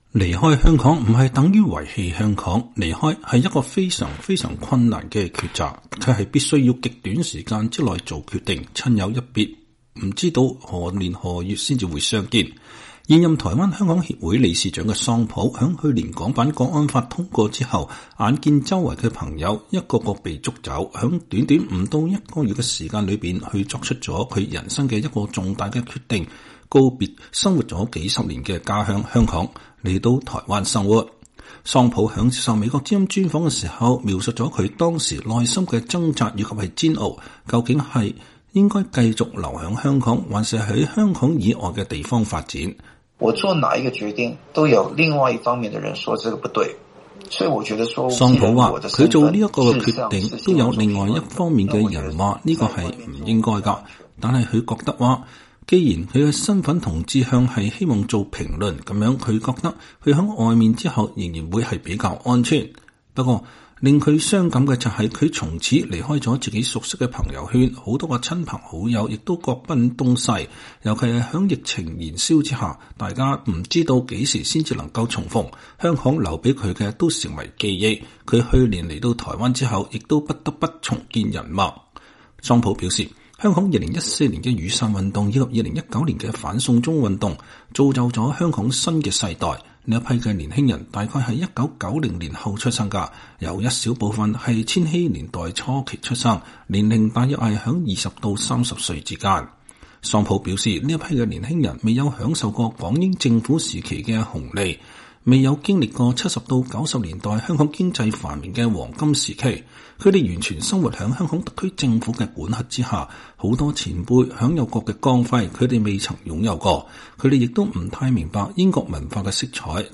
他在接受美國之音的專訪時用“不要死、好好過、等運到”來勉勵留守港人堅持到底。他提出，對抗中共獨裁的抗爭才剛剛開始。